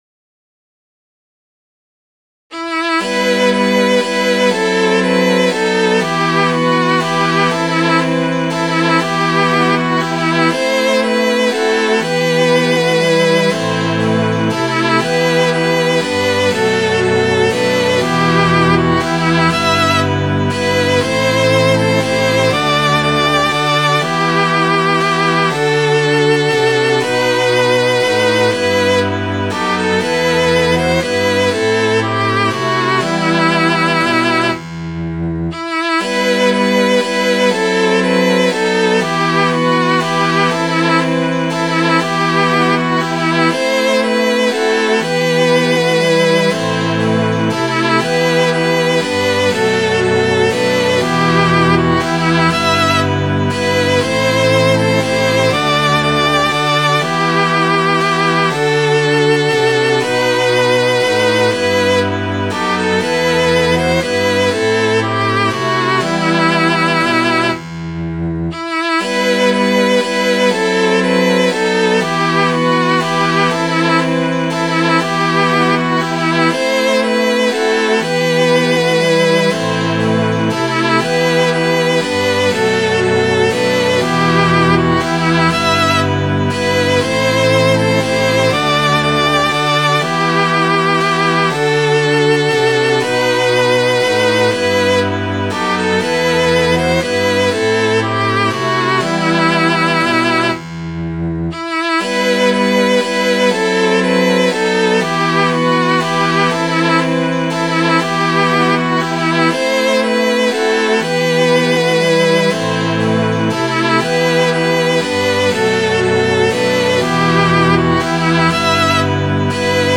Midi File, Lyrics and Information to I Must Live All Alone